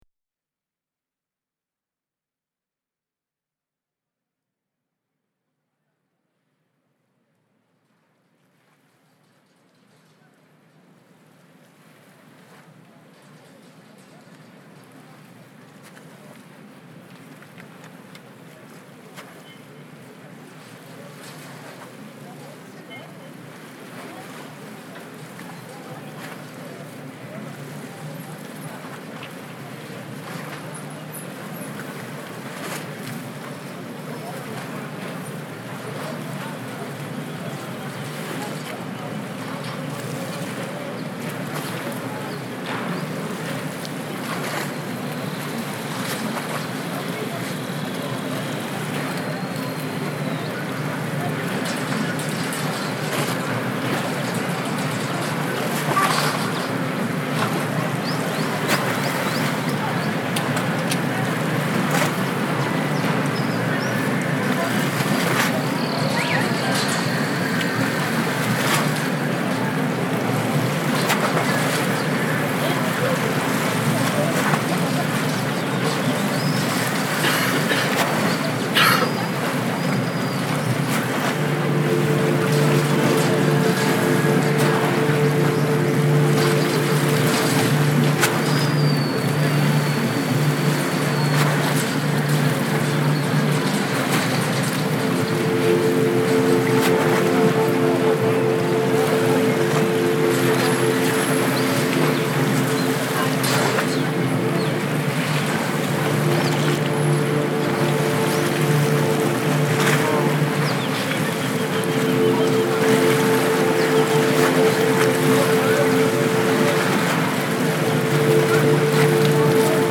realiza grabaciones de campo
que nos ofrece sonidos característicos de Turquía.